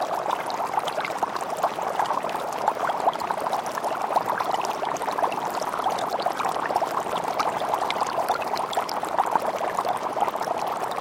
Звуки лаборатории
Здесь собраны реалистичные аудиоэффекты: от тихого гудения оборудования до звонких перекликов стеклянных колб.